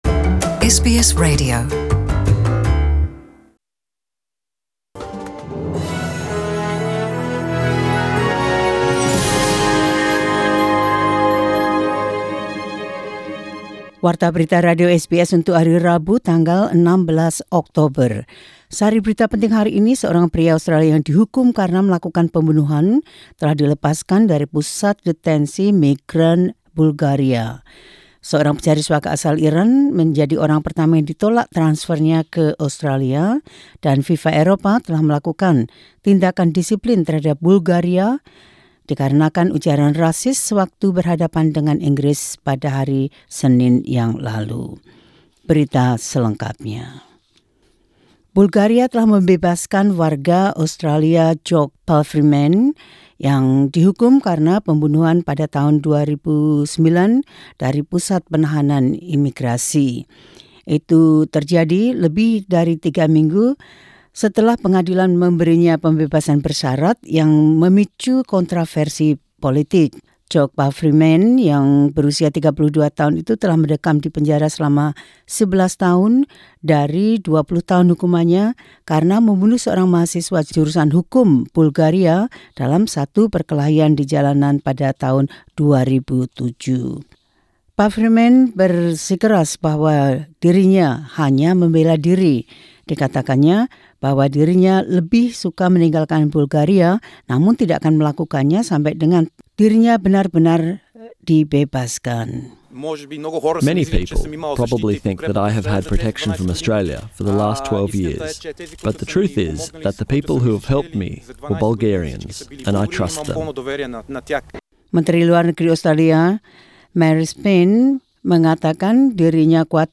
SBS Radio News in Indonesian 16 Oct 2019.